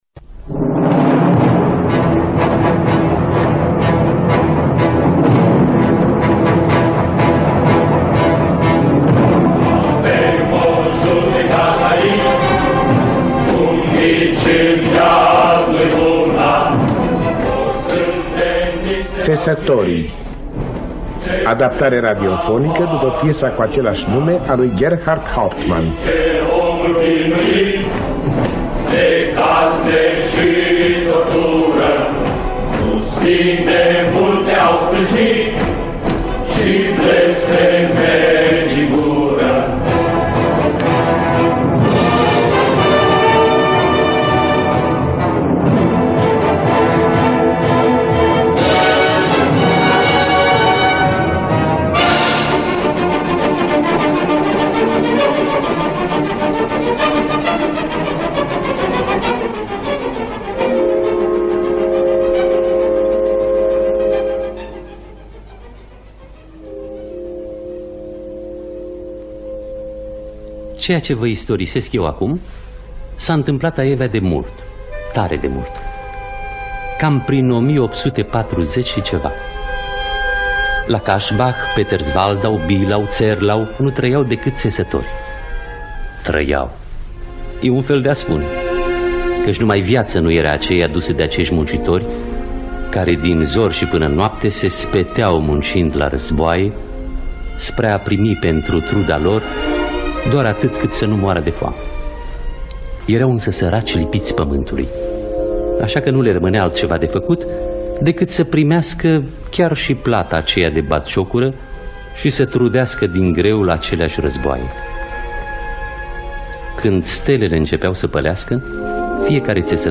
Țesătorii de Gerhart Hauptmann – Teatru Radiofonic Online
Traducerea şi adaptarea radiofonică de Paul B. Marian și Isaia Răcăciuni.